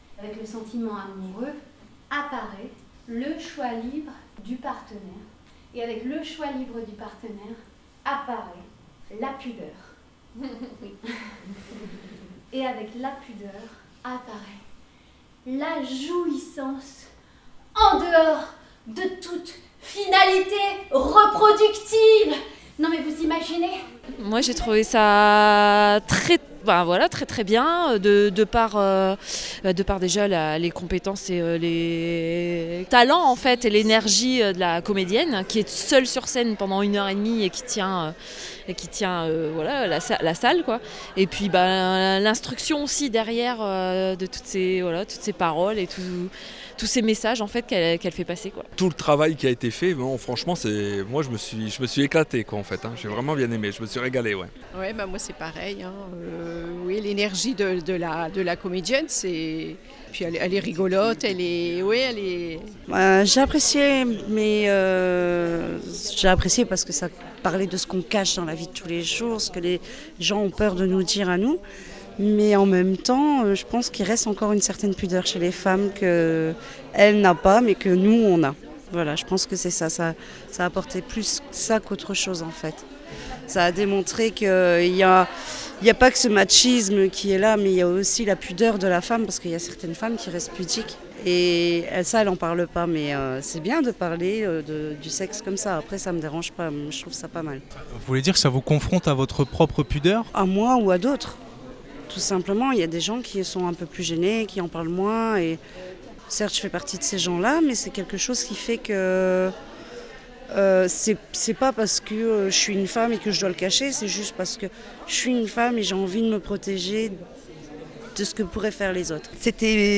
Micros rebelles reportage
Micro-trottoir
Quelques questions aux spectatrices.